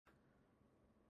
Аудиокнига Как полюбить продавать в кризис | Библиотека аудиокниг